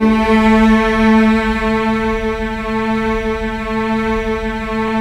Index of /90_sSampleCDs/Roland LCDP13 String Sections/STR_Symphonic/STR_Symph. Slow